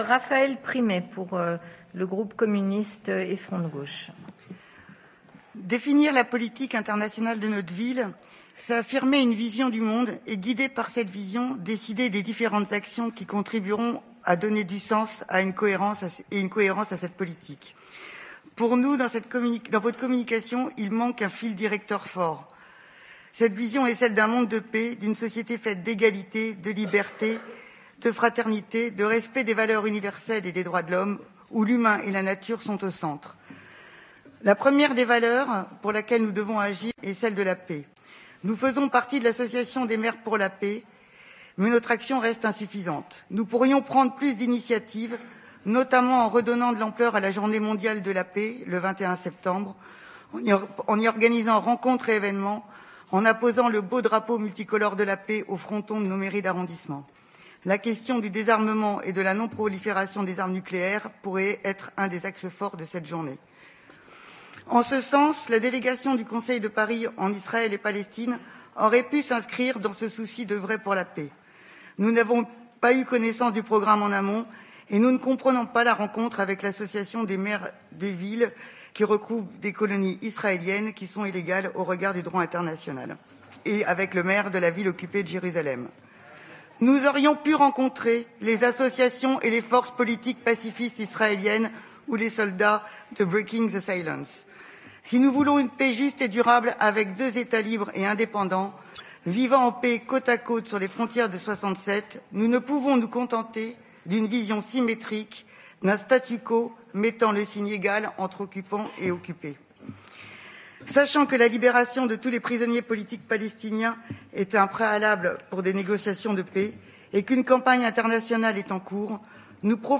Action internationale de la Ville de Paris - Anne Hidalgo s'emporte contre une élue du groupe PC-FG au Conseil de Paris.mp3 (7.62 Mo)